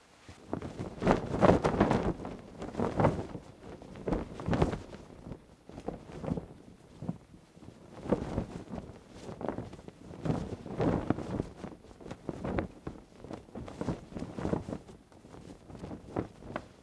cloth_sail_L_optimized.wav